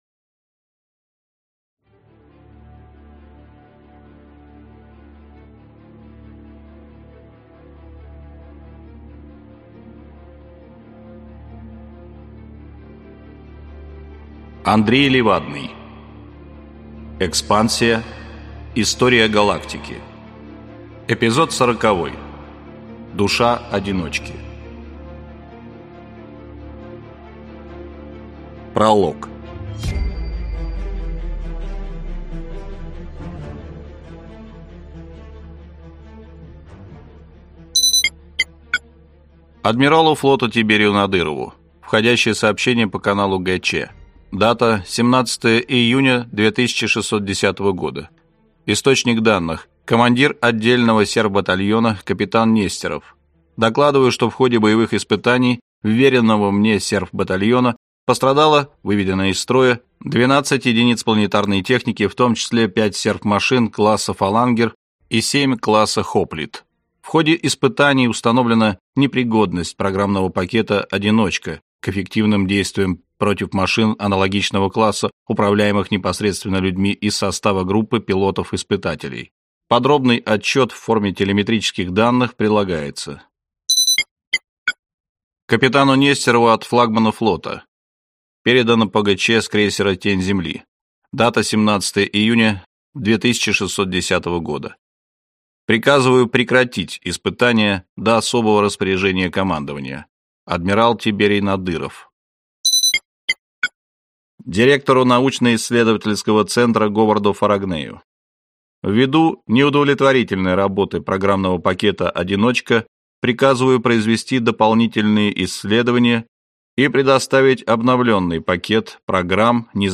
Аудиокнига Душа «Одиночки» | Библиотека аудиокниг